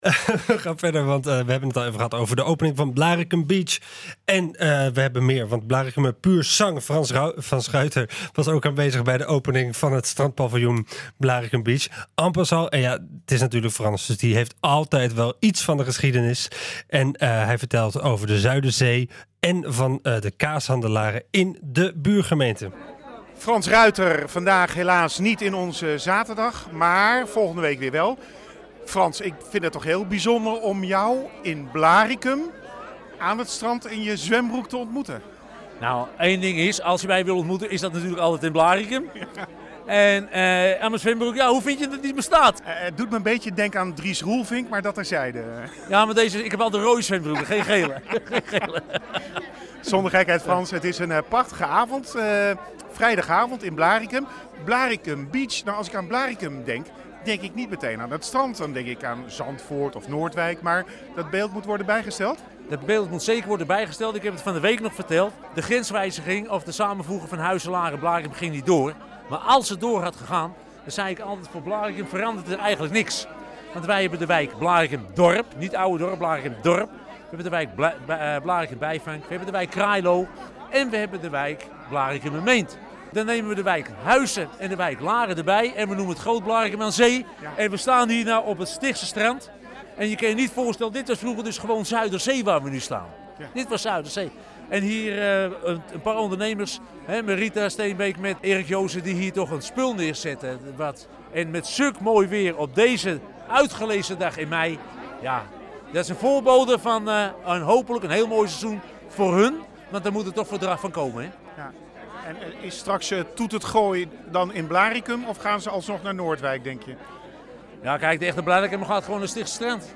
interviewde